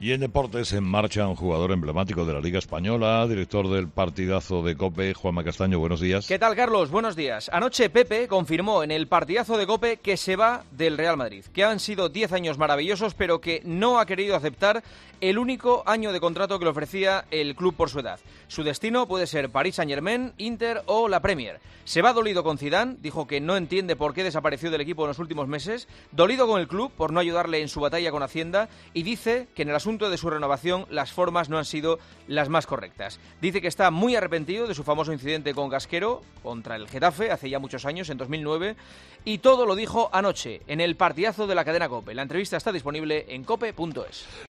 AUDIO: La entrevista a Pepe en 'El Partidazo', en la actualidad deportiva con Juanma Castaño en 'Herrera en COPE'.